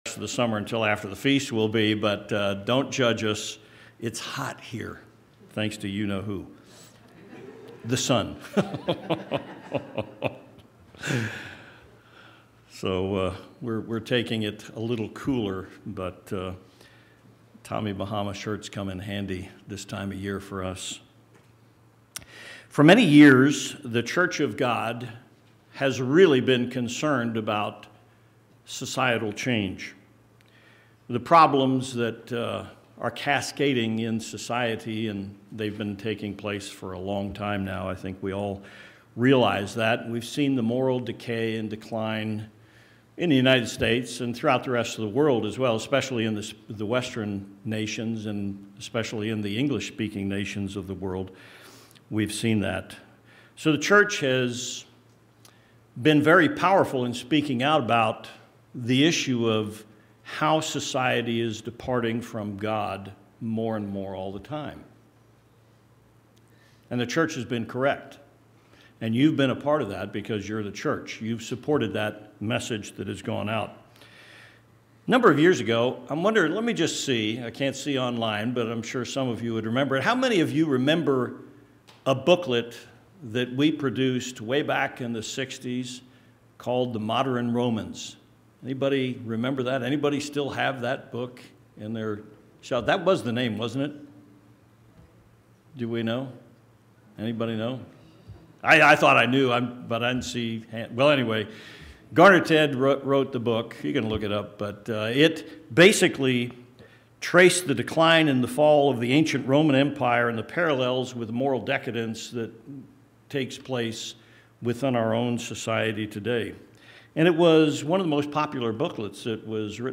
One of the most important themes of Bible Prophecy is a warning to the leaders of the world. This sermon discusses that warning and our individual and collective responsibility as members.